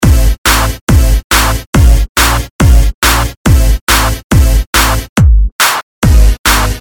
合成器和鼓的循环
描述：舞蹈 "140 "和 "140bpm"。
Tag: 140 bpm Dance Loops Synth Loops 1.14 MB wav Key : Unknown